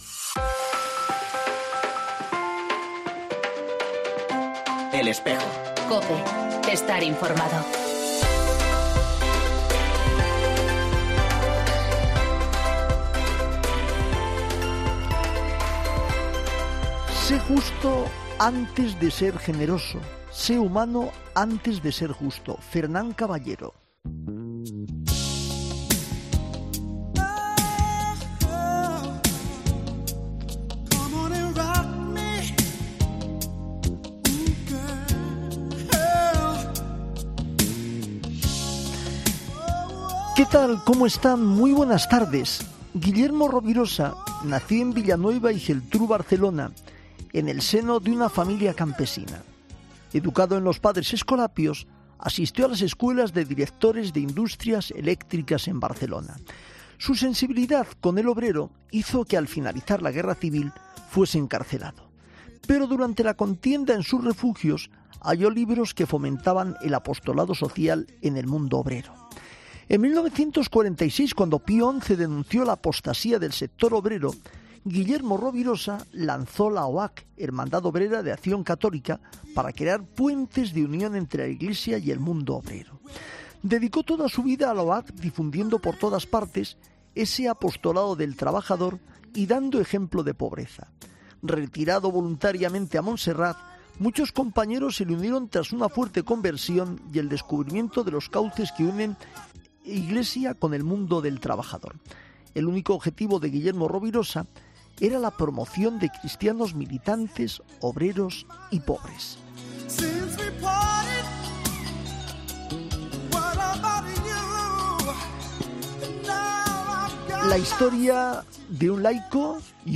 En El Espejo 15 febrero 2020: Entrevista